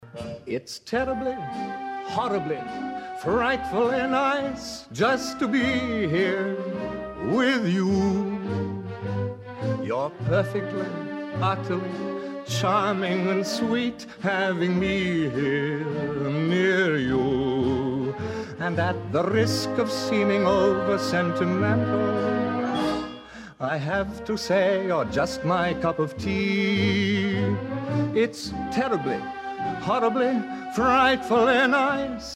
Original Cast